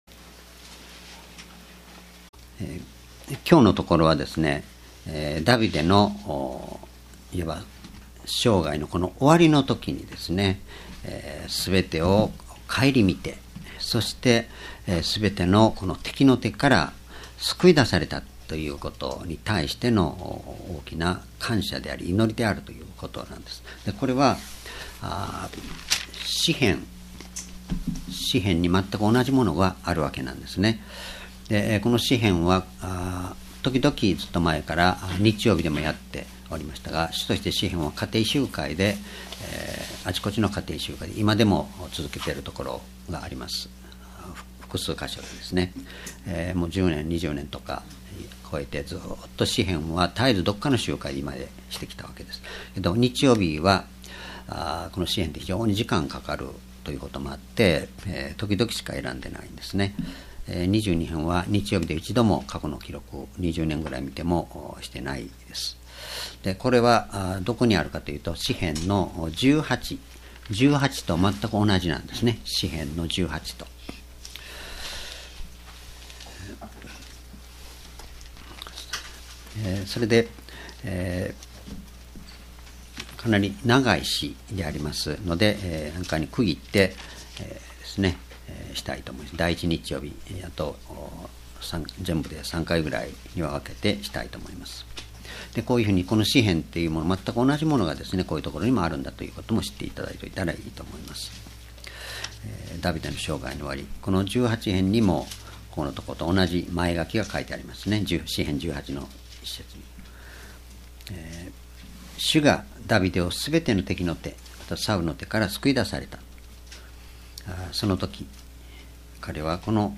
主日礼拝日時 ２０１４年１１月２日 聖書講話箇所 サムエル記下 ２２の１－２０ 「主はわが岩」 ※視聴できない場合は をクリックしてください。